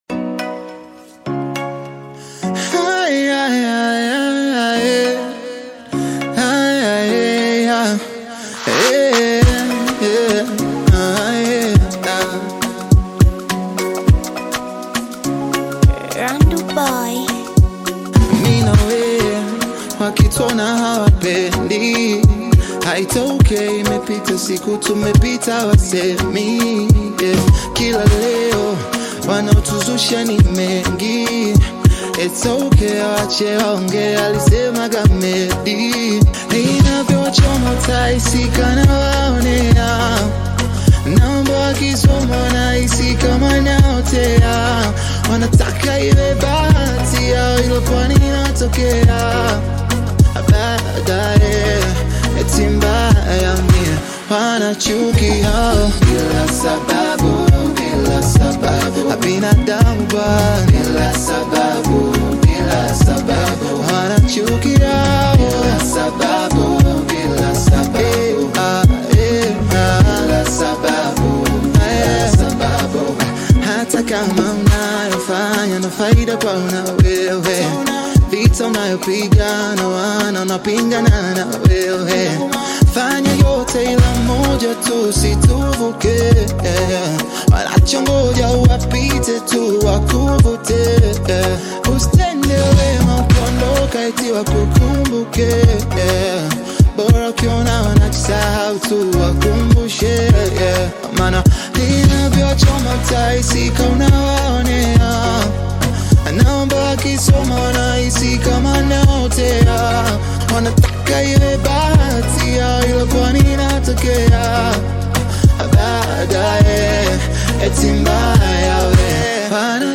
Tanzanian upcoming bongo flava artist, singer and songwriter
Love bongo flava and RnB song